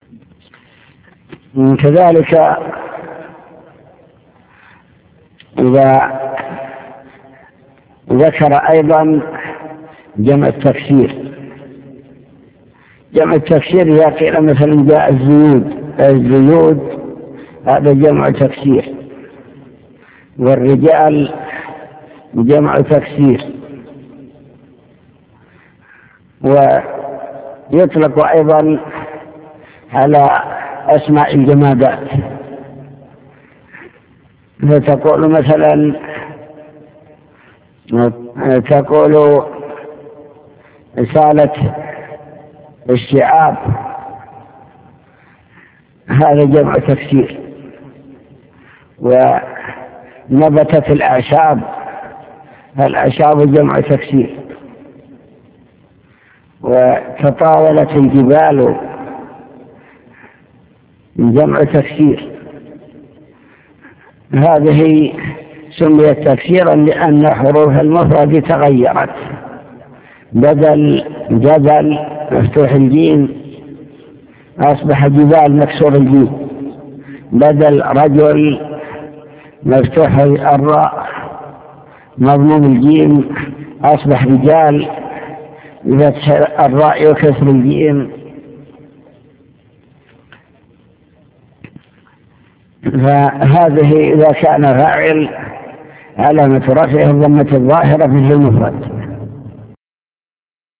المكتبة الصوتية  تسجيلات - كتب  شرح كتاب الآجرومية باب الفاعل أقسام الفاعل أنواع الفاعل الظاهر